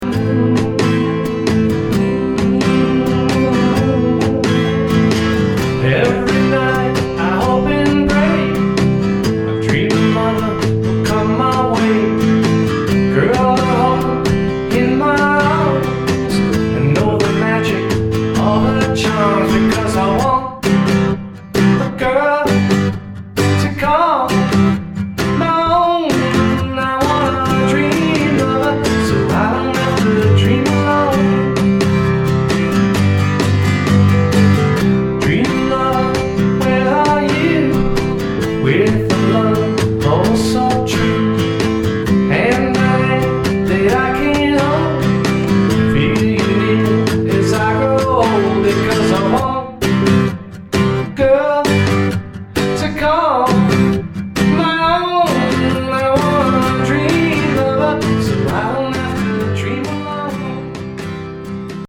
zeitlose Rock/Pock Klassiker
50's Rock n' Roll